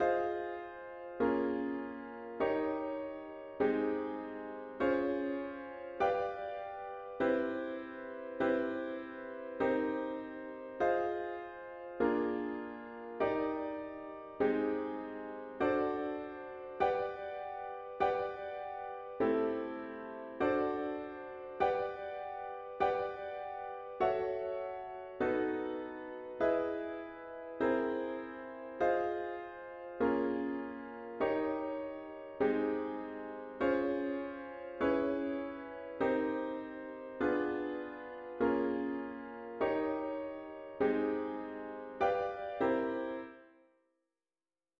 An audio of the chords only version is